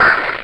scrump_hurt.ogg